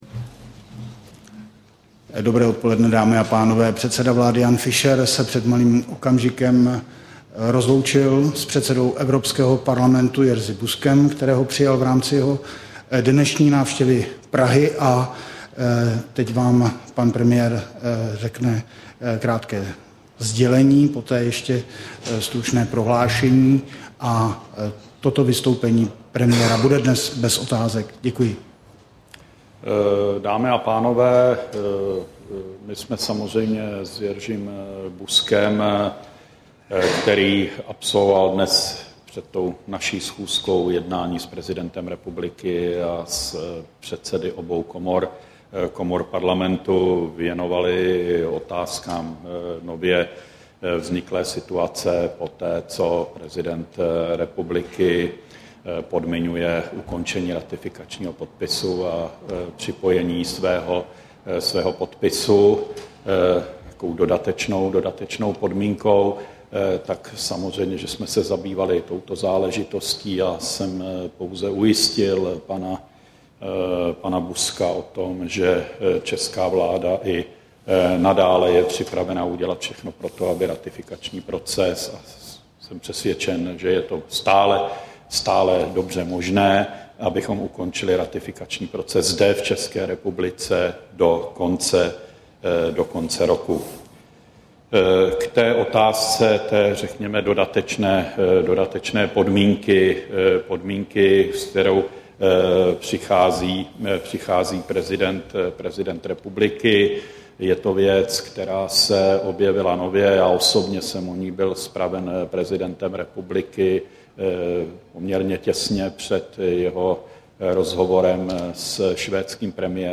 Tisková konference po jednání premiéra s předsedou Evropského parlamentu Jerzy Buzkem, 9. října 2009